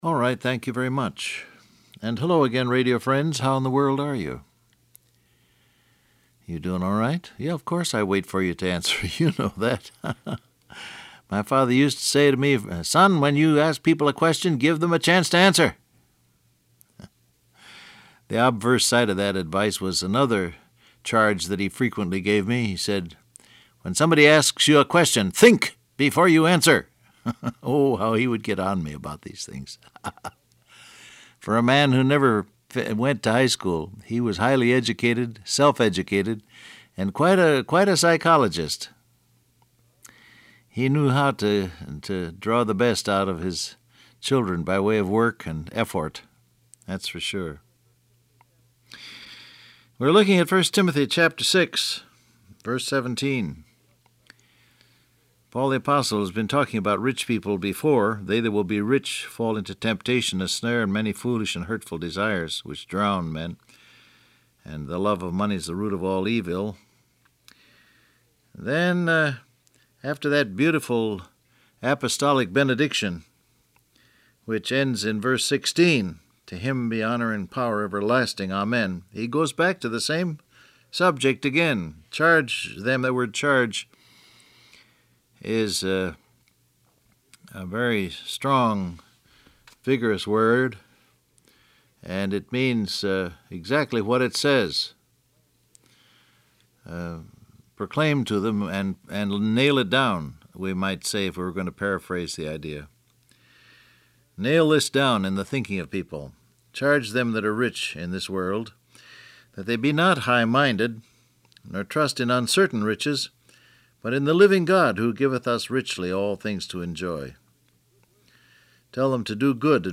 Download Audio Print Broadcast #6773 Scripture: 1 Timothy 6:17 Topics: Living God , Money , Wealth Transcript Facebook Twitter WhatsApp Alright, thank you very much.